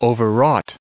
added pronounciation and merriam webster audio
778_overwrought.ogg